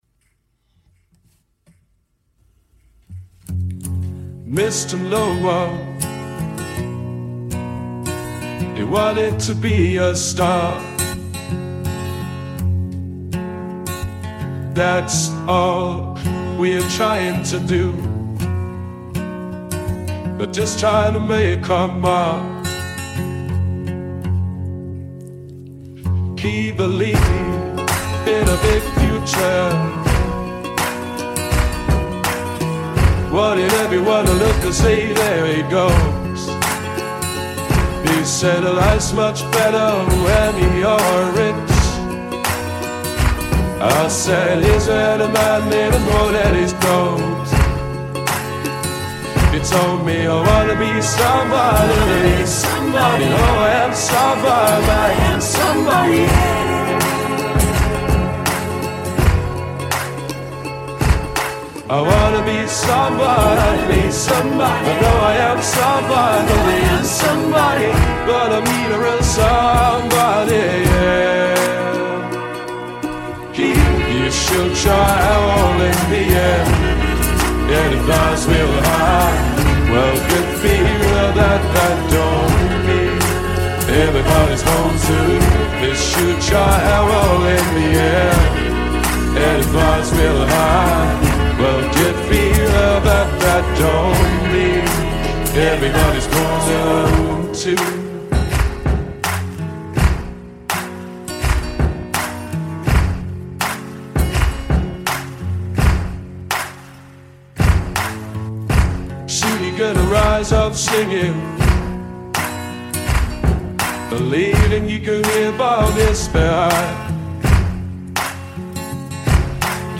un gruppo indie-pop con sede a Londra formatosi nel 2011.